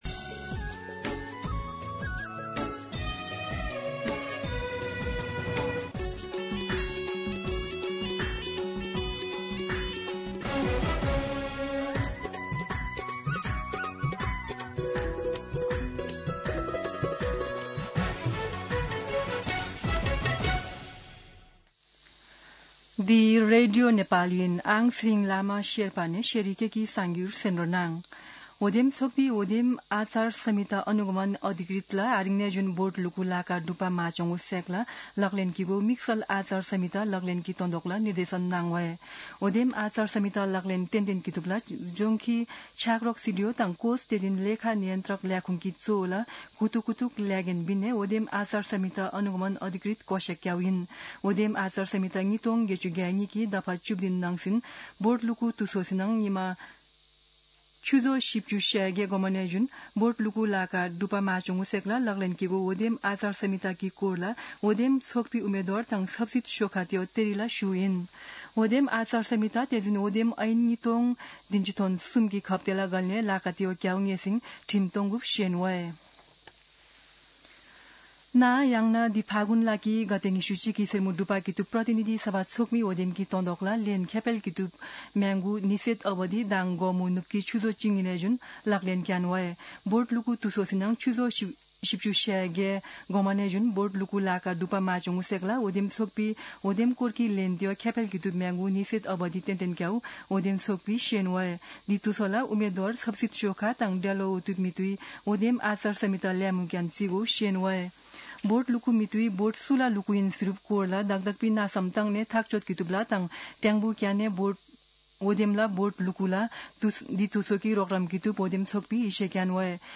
शेर्पा भाषाको समाचार : १९ फागुन , २०८२
Sherpa-News-19.mp3